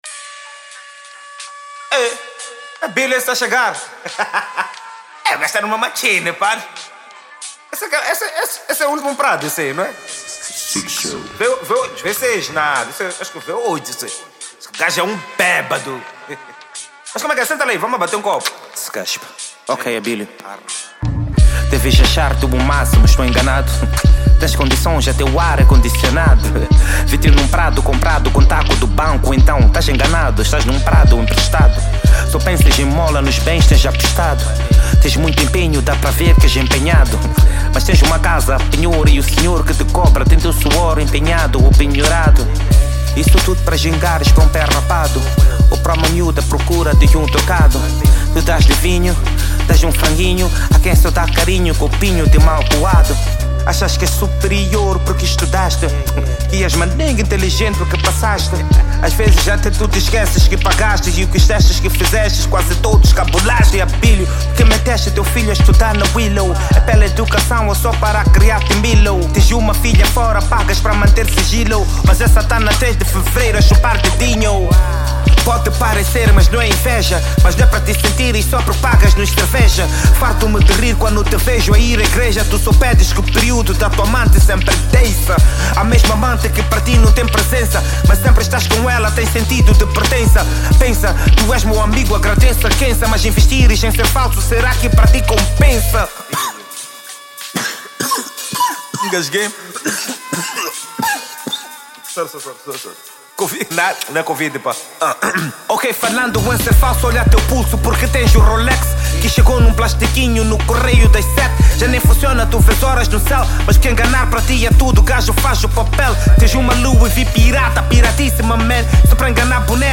Hip-Hop/Rap Ano de Lançamento